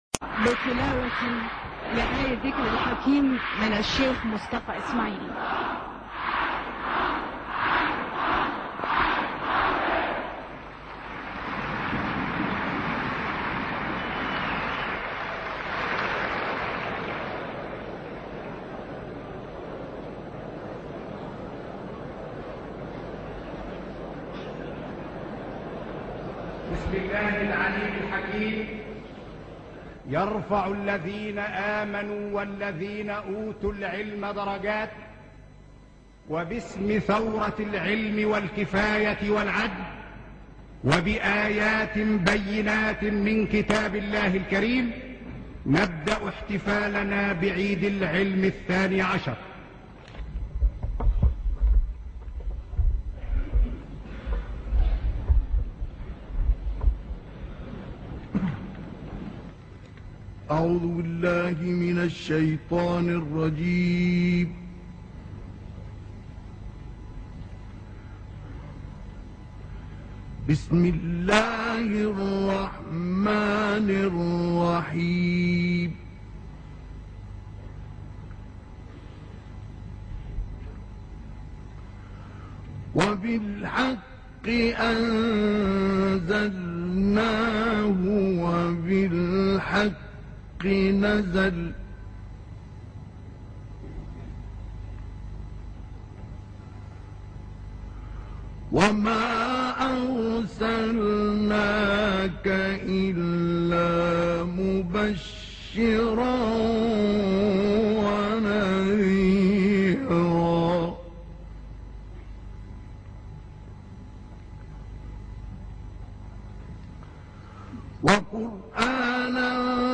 • القارئ :مصطفى إسماعيل
• نوع المصحف : تسجيلات خارجية غير معروفة المكان
• الرواية : حفص عن عاصم
الإسراء-العلق | بمناسبة عيد العلم بحضور الرئيس جمال عبدالناصر